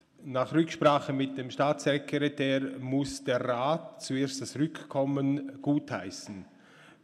13.6.2019Wortmeldung
Ratspräsident:
Session des Kantonsrates vom 11. bis 13. Juni 2019